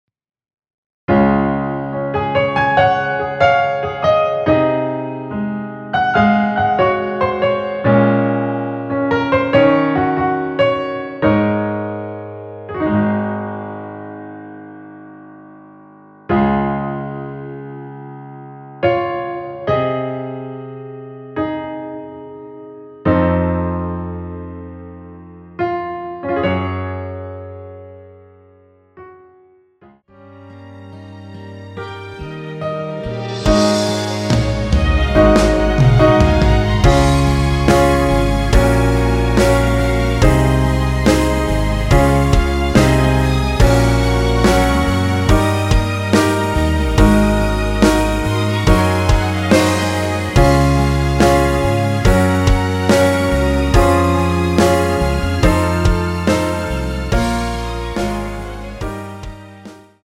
원키에서(+4)올린 MR입니다.
Db
앞부분30초, 뒷부분30초씩 편집해서 올려 드리고 있습니다.